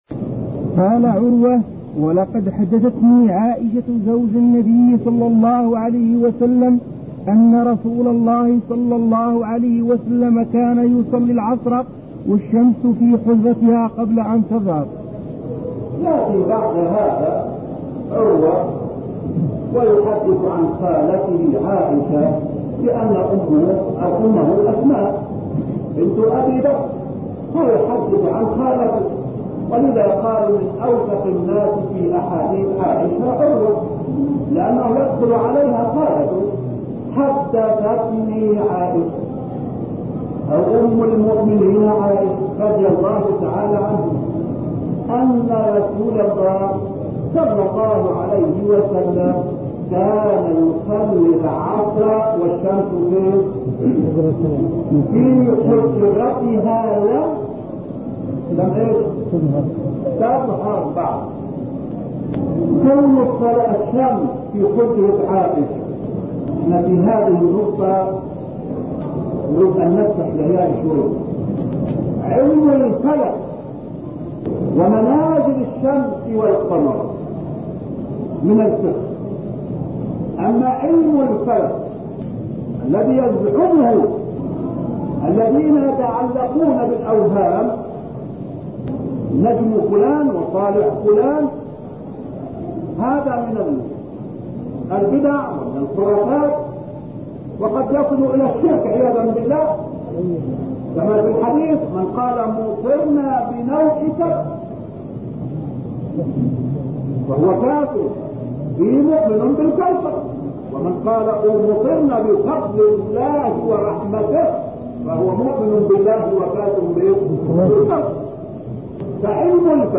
تاريخ النشر ٨ جمادى الأولى ١٤٤٧ المكان: المسجد النبوي الشيخ: فضيلة الشيخ عطية بن محمد سالم فضيلة الشيخ عطية بن محمد سالم 2كان يصلي والشمس في حجرتها